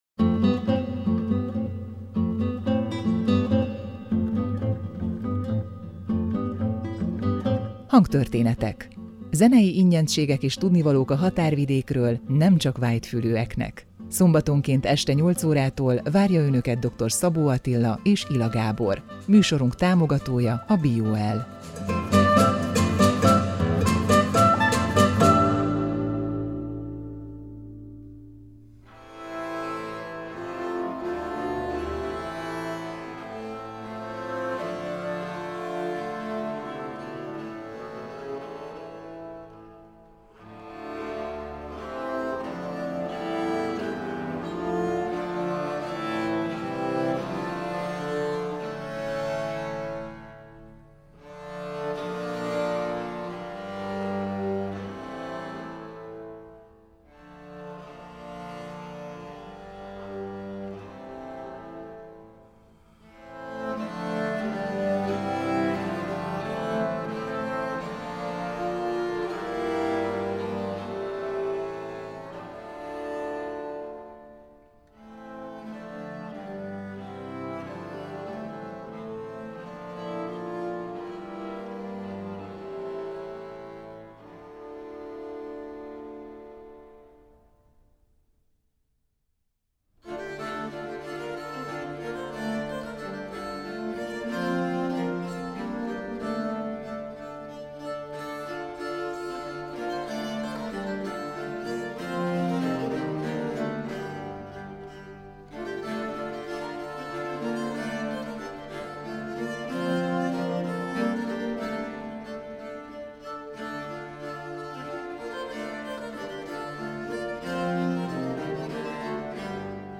Track Nr. 1.: Haydn: a. Sonata No. 9 in D Major Hob.XVI:4 I. (Moderato) b. Sonata No. 12 in A Major Hob.XVI:12 II. Menuet – Rudolf Buchbinder